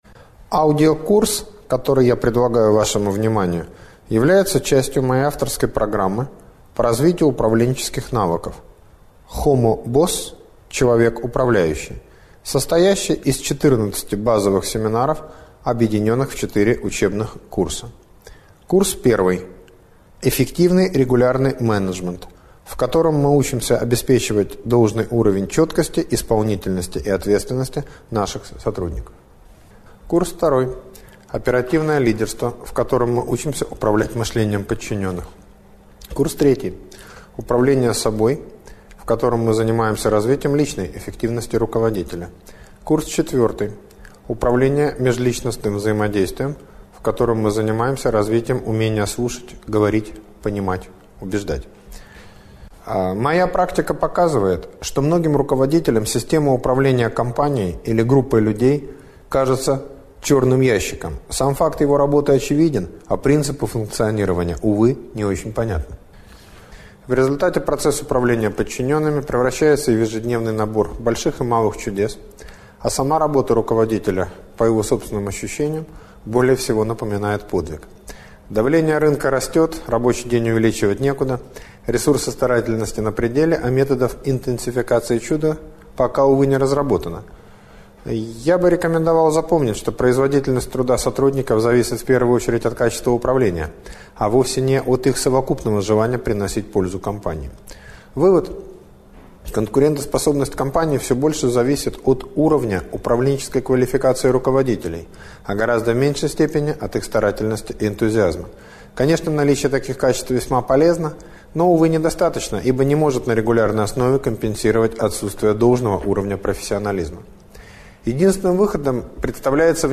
Аудиокнига Управление мышлением подчиненных: центрирующие парадигмы | Библиотека аудиокниг